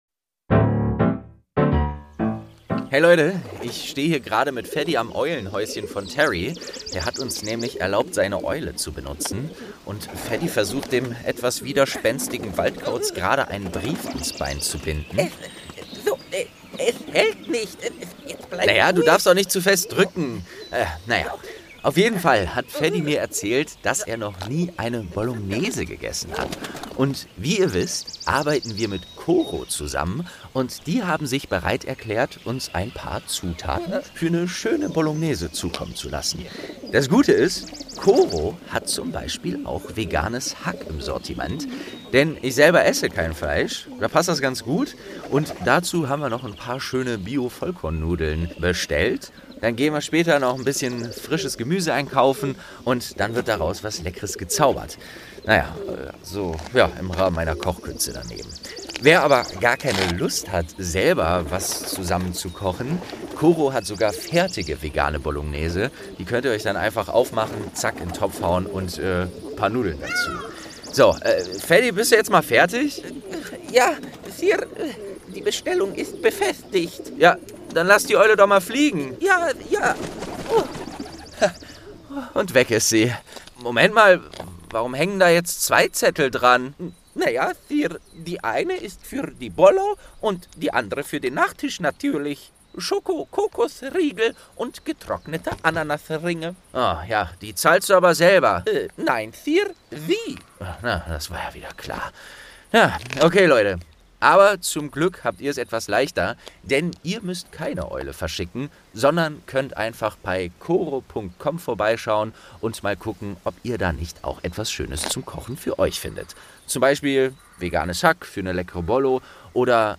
Geschichten aus dem Eberkopf - Ein Harry Potter Hörspiel-Podcast